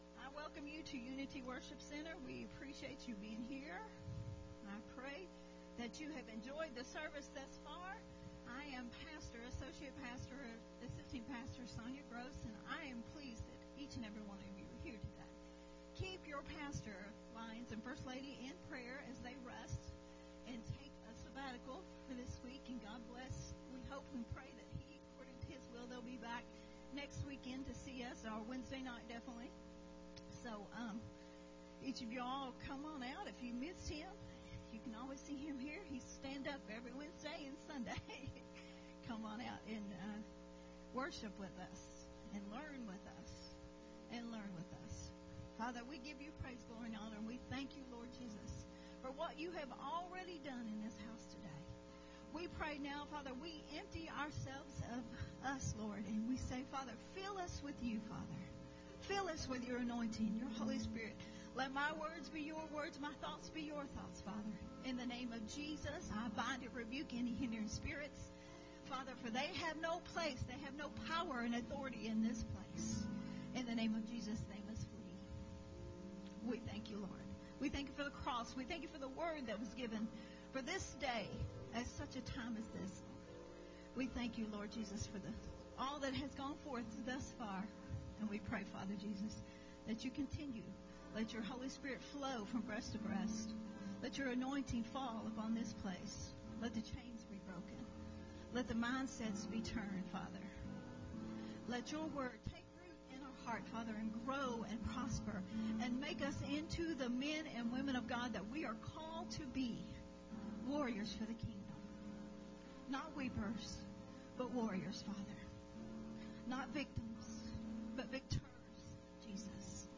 a Sunday Morning Message